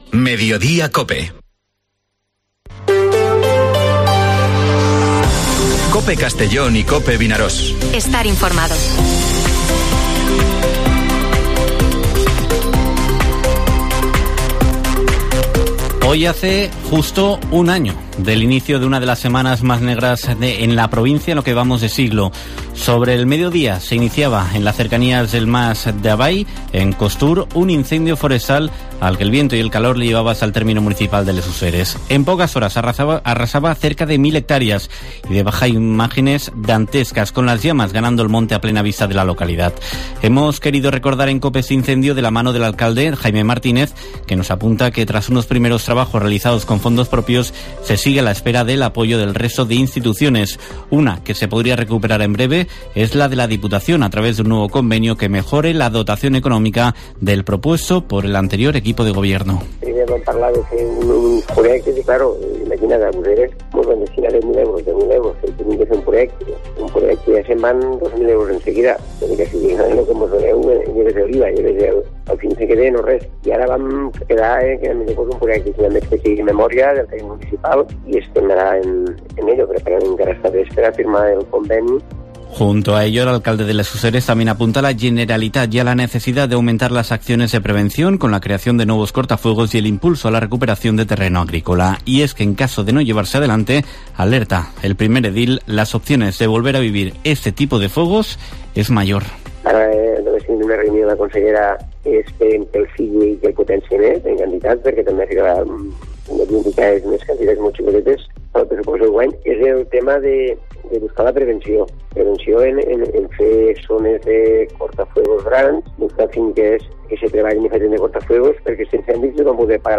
Informativo Mediodía COPE en la provincia de Castellón (14/08/2023)